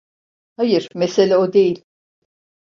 Pronounced as (IPA)
/me.se.le/